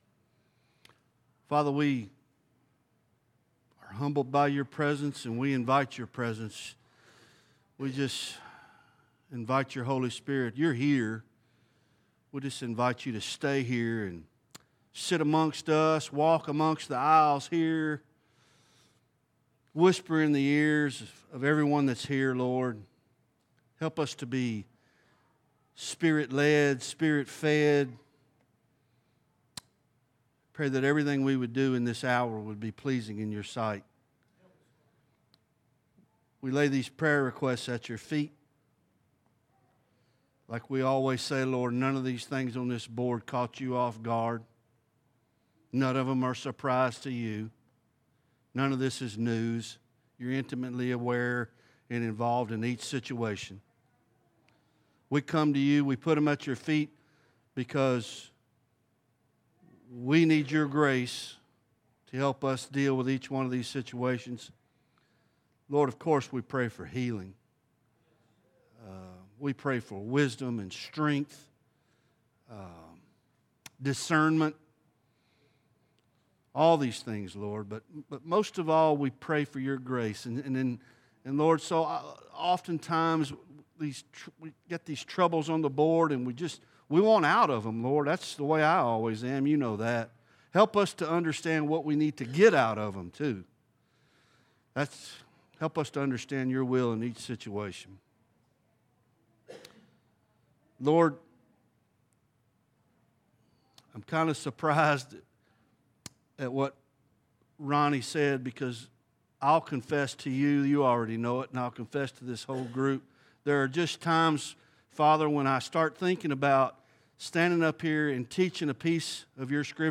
Bible Study Isaiah Ch 8